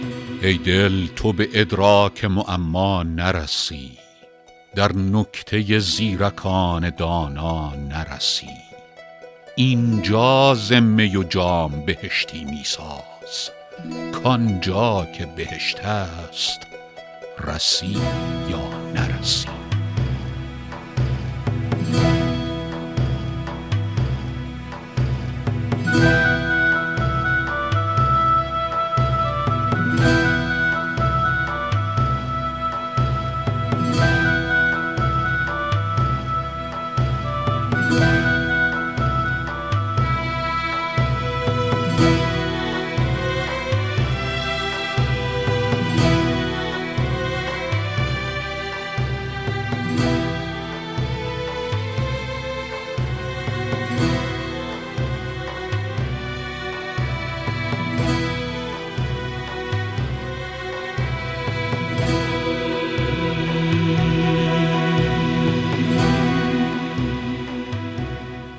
رباعی ۴ به خوانش فریدون فرح‌اندوز